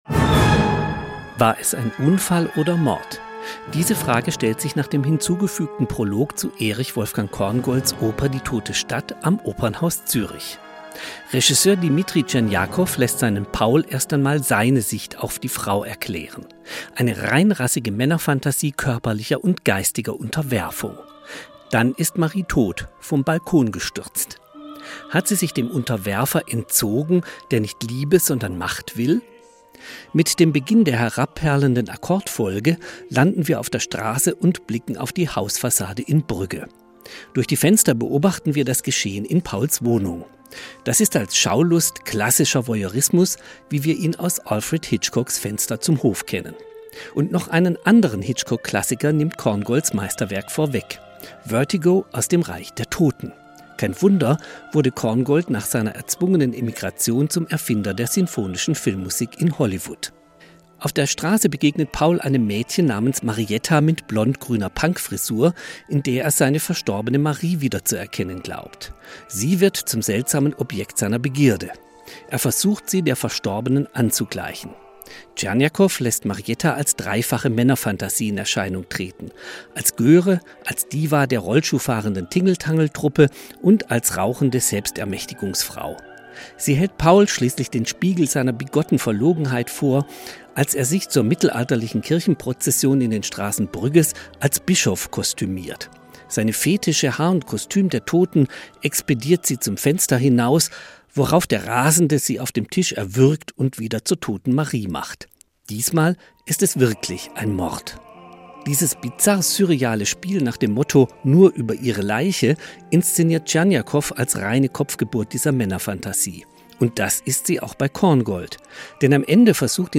Korngolds Oper „Die tote Stadt“ in Zürich | Opernkritik